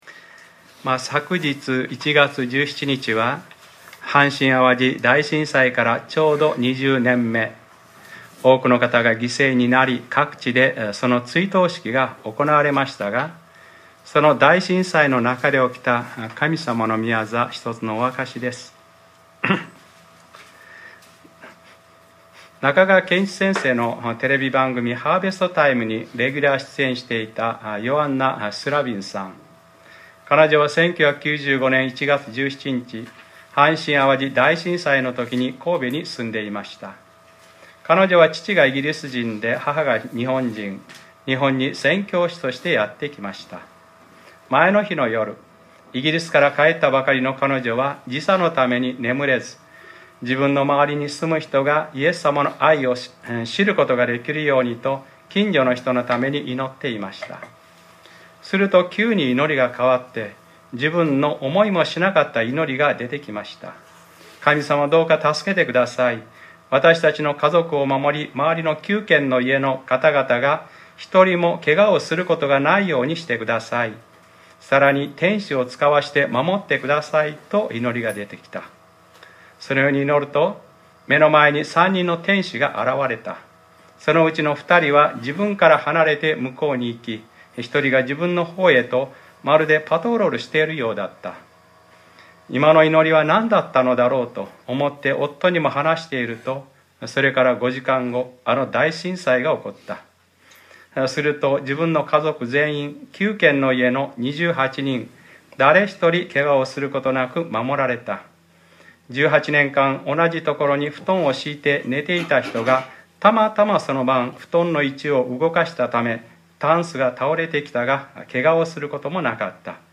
2015年01月18日）礼拝説教 『ルカｰ５２：祝福あれ。主の御名によって来られる方に』